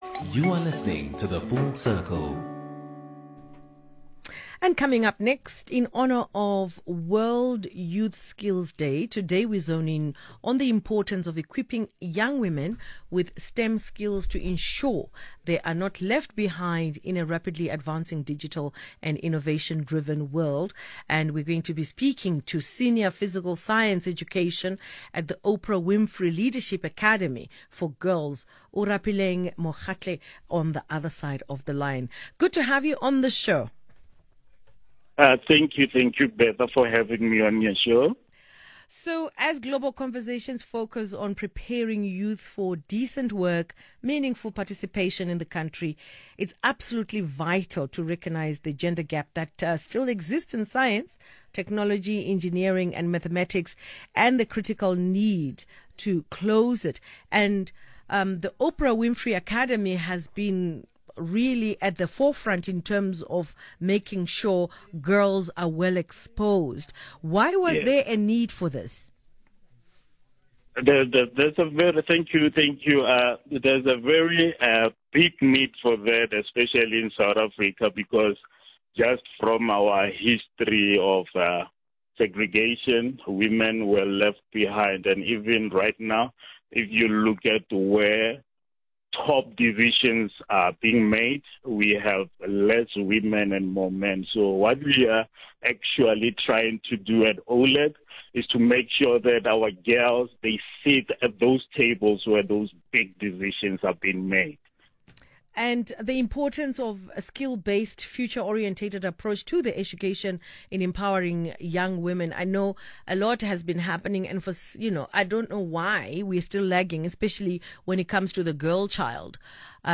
Radio Interview – SA FM – Oprah Winfrey Leadership Academy for Girls